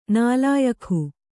♪ nālāyakhu